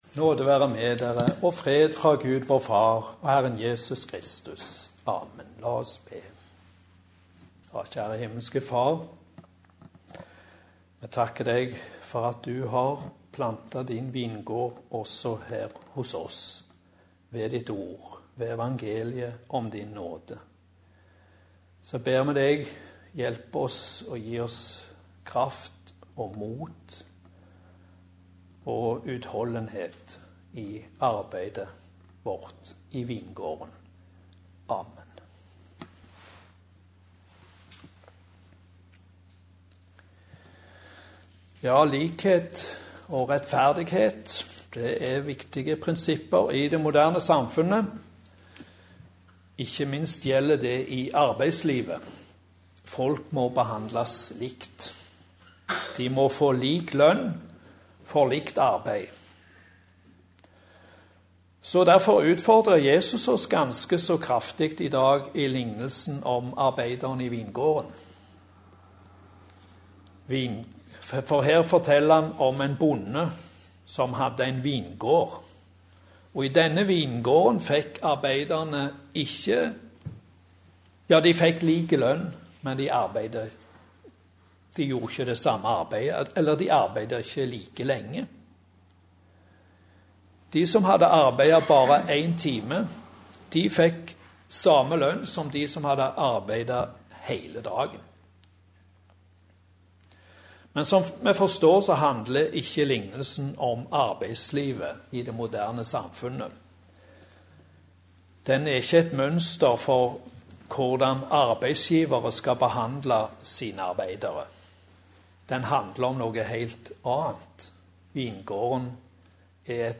Preken på Vingårdssøndagen